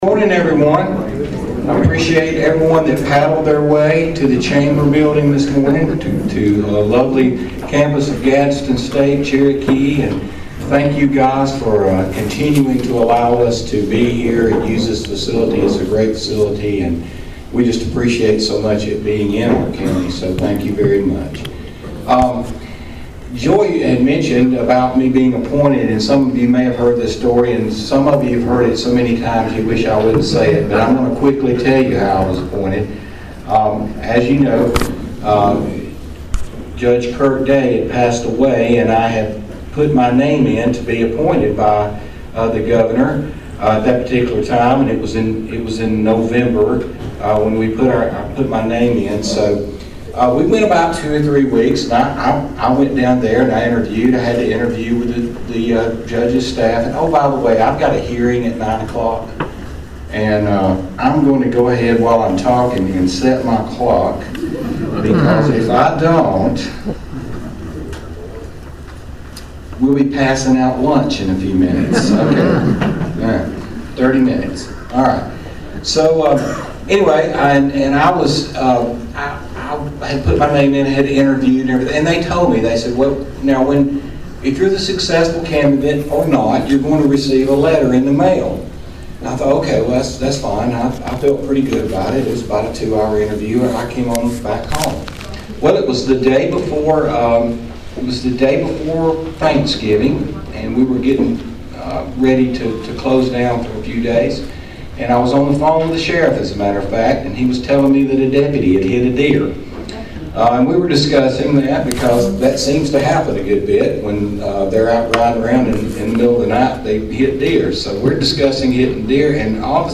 The State of the County Address was held Thursday morning, February, 13th at the Cherokee Chamber Office, located on the Campus of Gadsden State Cherokee Campus.
(With a portion of the story involving a spot on impression of the Governor) Burgess address went into financial details for the County Government for the 2024 year. He began by addressing Ad Valorem Tax Distributions for the 2024 Tax year. He then broke down the numbers on the County Sales Tax rate and percentage, and how incoming tax revenue has compared to inflation.